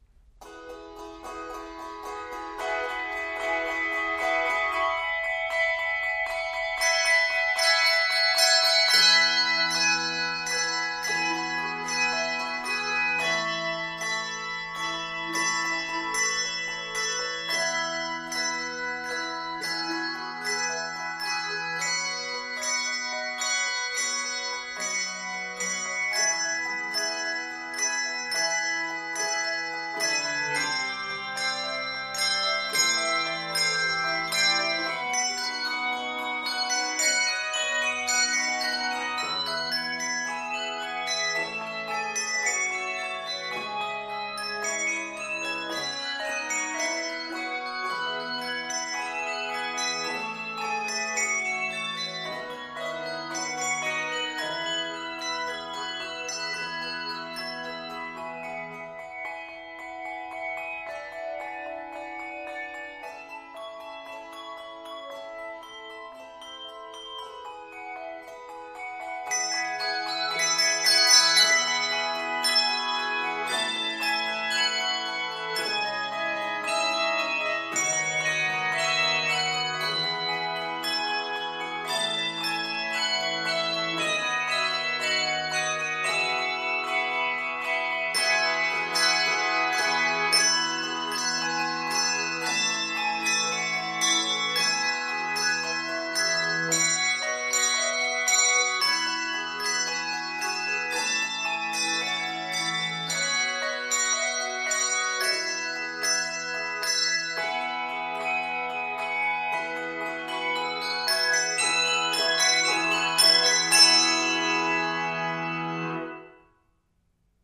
handbells
pulses with energy
Key of E Major. 54 measures.